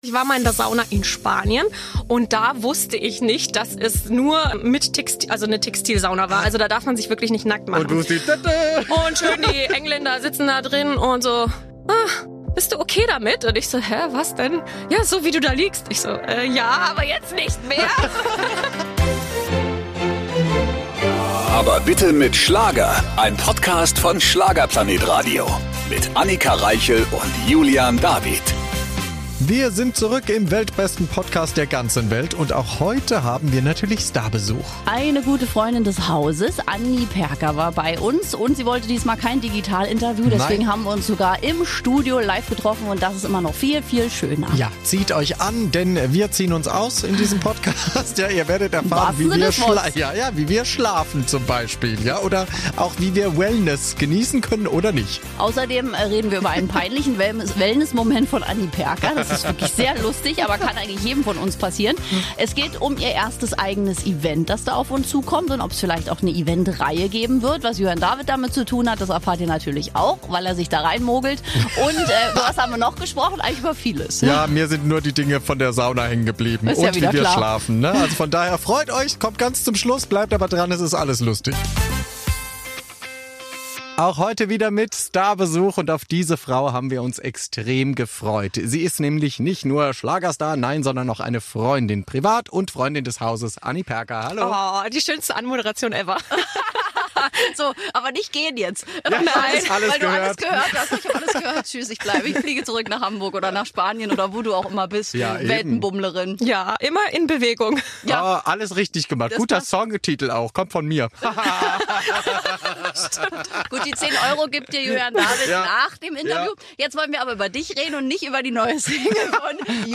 Anita Hofmann ist zu Gast im Podcast und stellt uns ihr erstes Soloalbum vor.